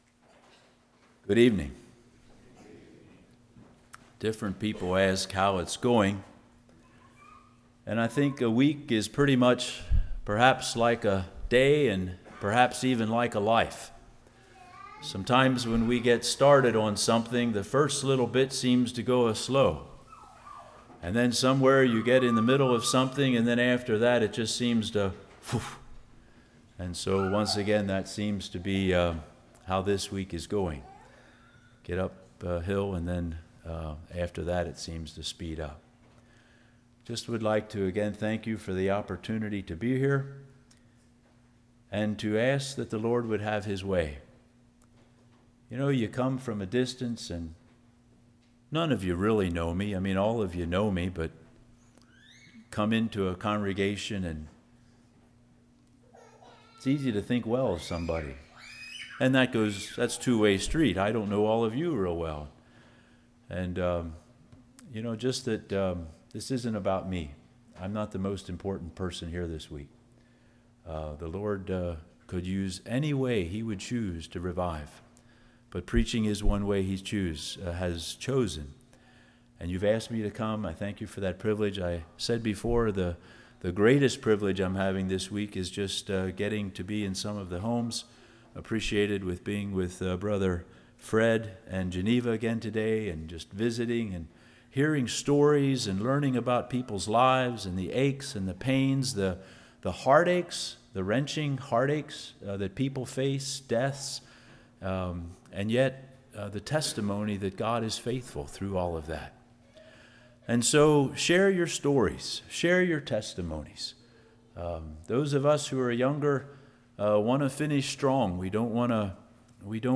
Fall Revival 2012 Passage: 2 Kings 6:8-18 Service Type: Revival Fear Confidence Christ « Where is Your Treasure?